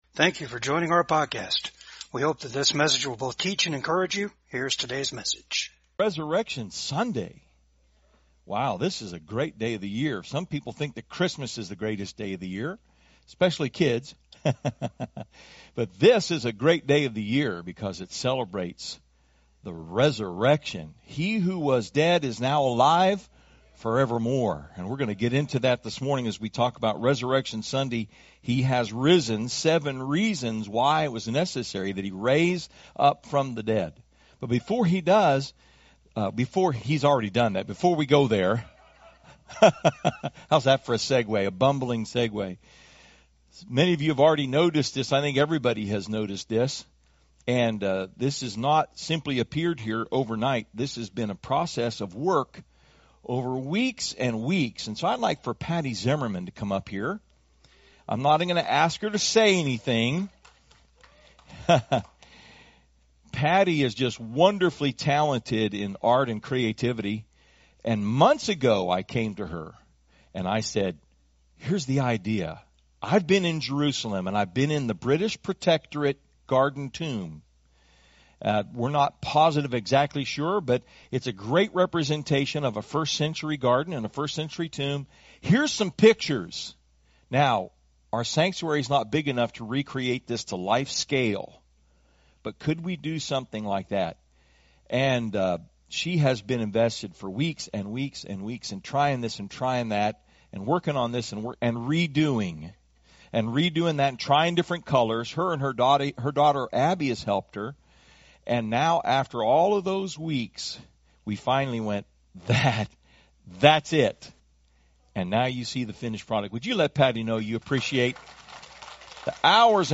2 Corinthians 4:14 Service Type: VCAG SUNDAY SERVICE HE IS RISEN... 1.